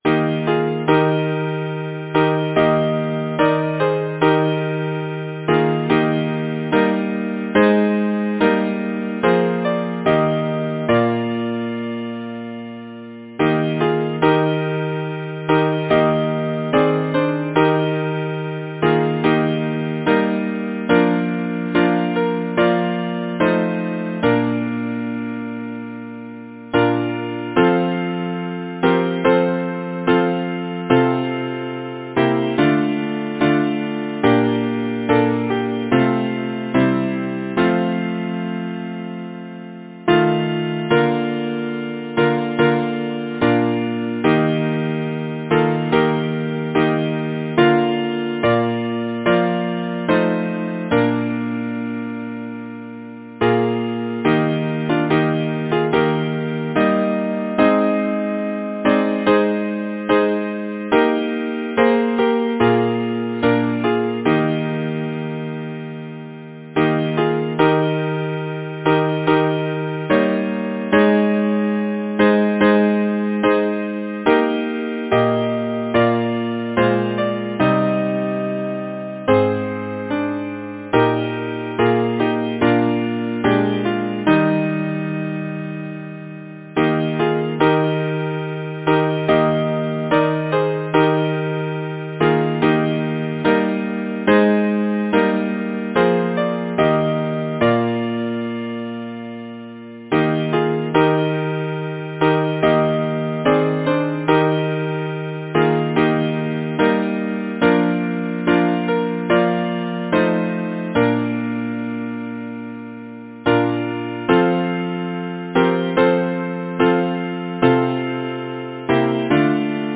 Title: The harp of memory Composer: Edward Roberts Lyricist: Number of voices: 4vv Voicing: SATB Genre: Secular, Partsong
Language: English Instruments: A cappella